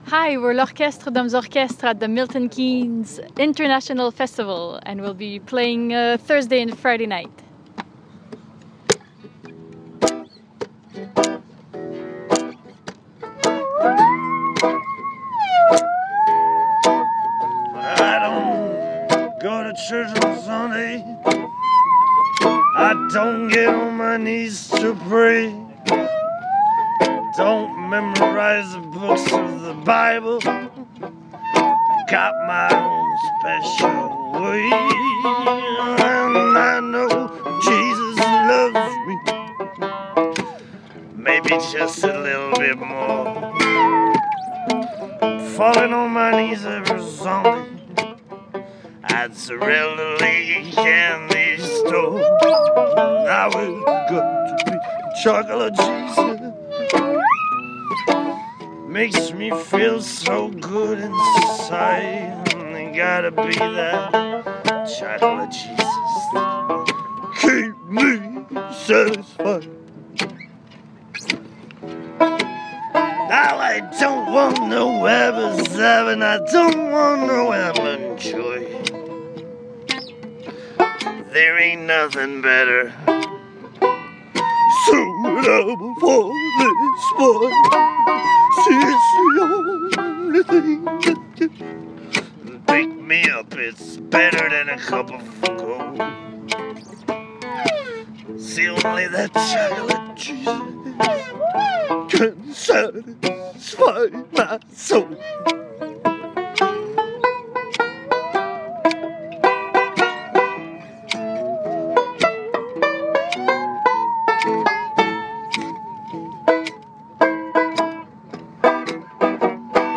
backstage at the Milton Keynes International Festival